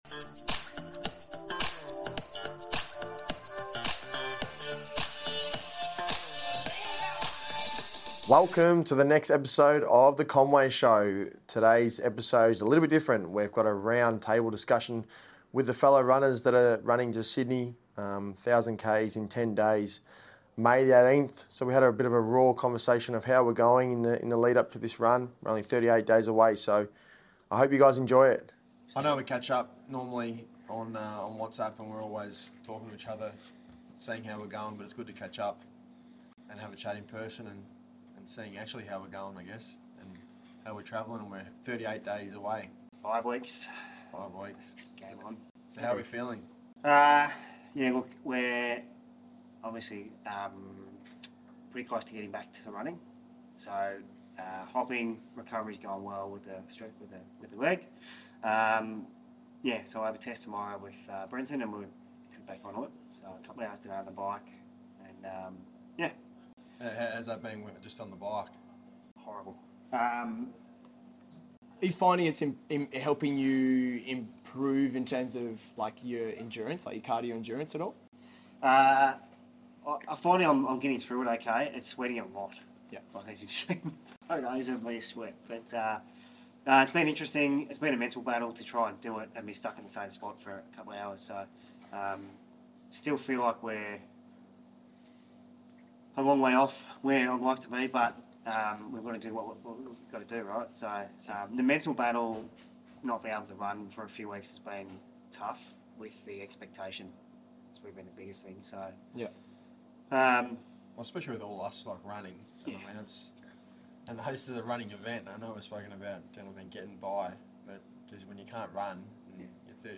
An open and raw conversation on the ins and outs of how we are all feeling physically and mentally, as well as how we are all finding the training and preparation.